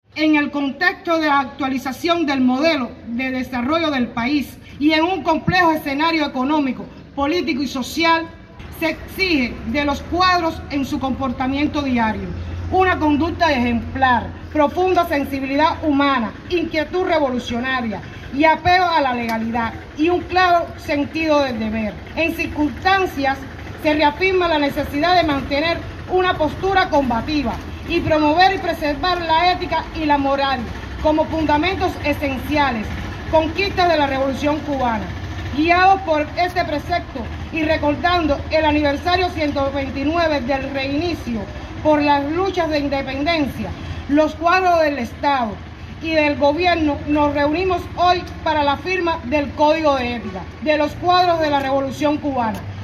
La ceremonia se desarrolló en el parque Pepe Roque, de la localidad.